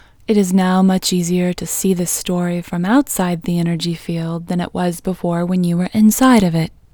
OUT Technique Female English 16